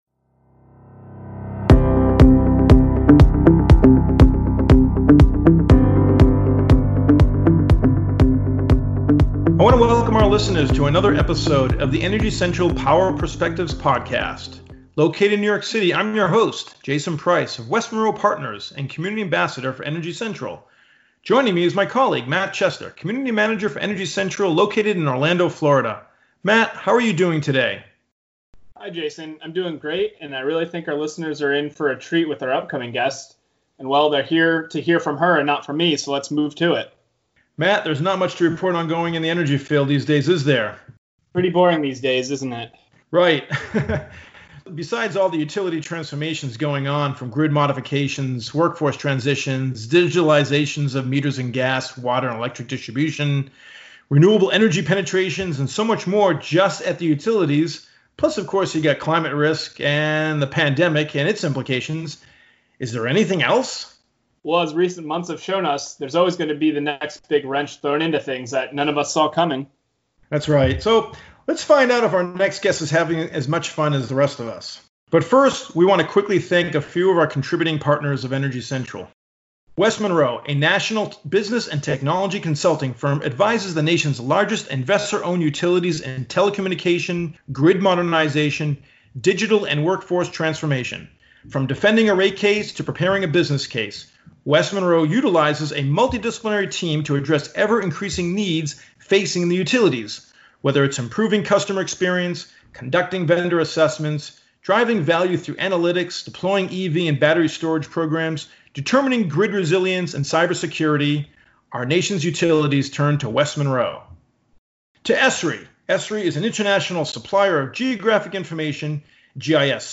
joined in the podcast booth